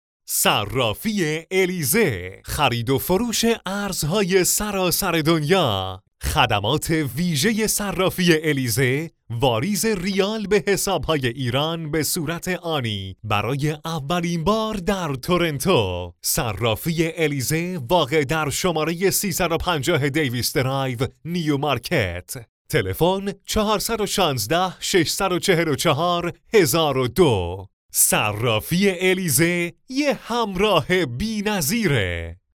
Male
Young
Adult
Excahange-Commercial